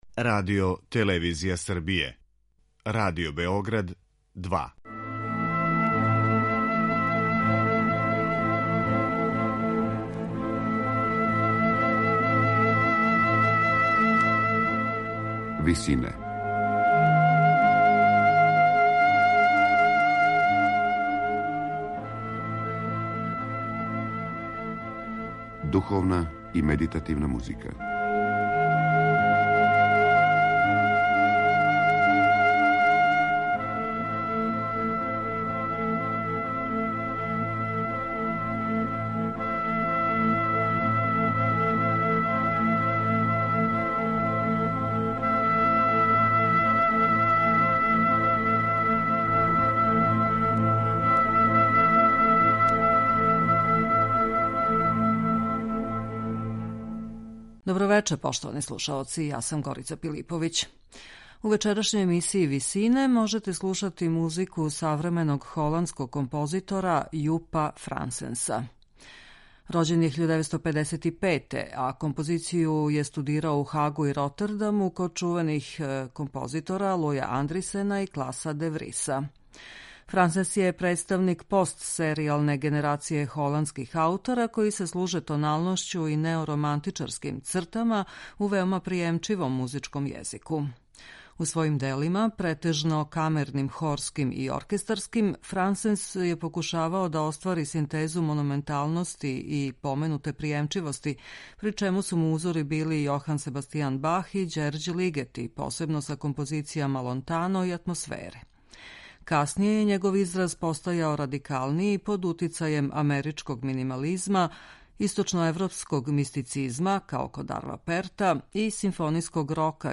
медитативне и духовне композиције
статичној дијатоници